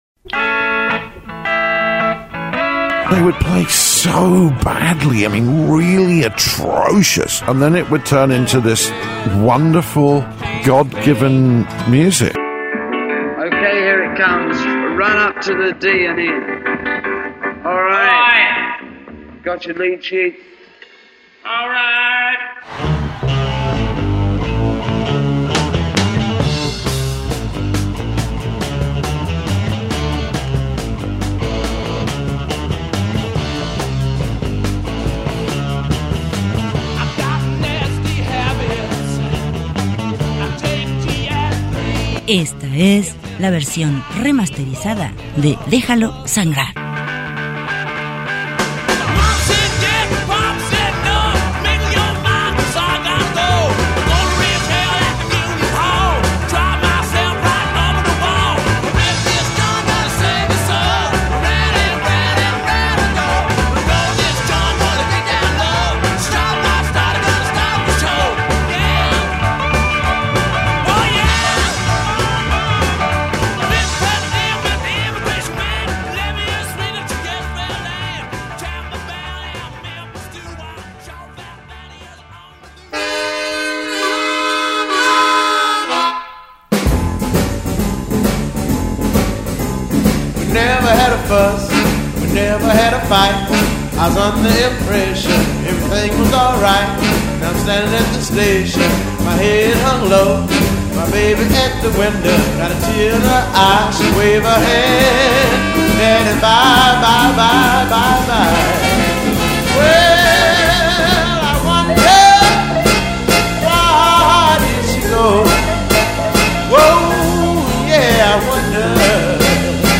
música negra